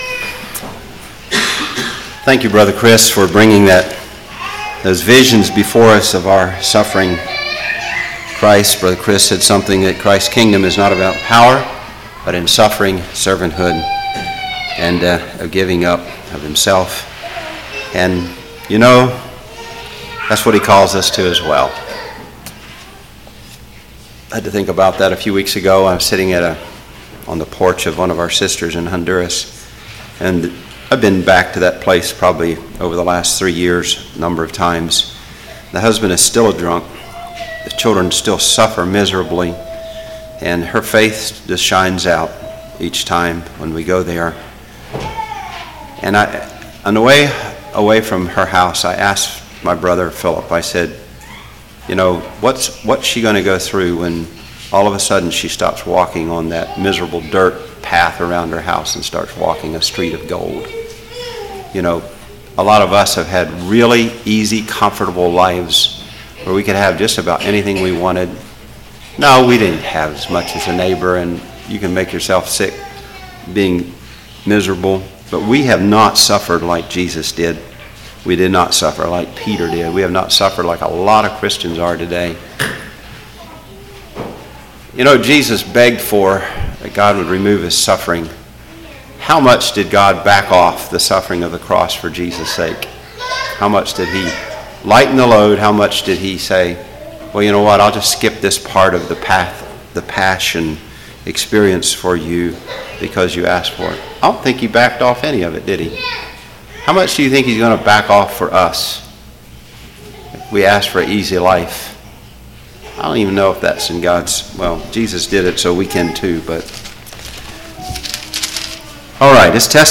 Sermons
Congregation: Susquehanna Valley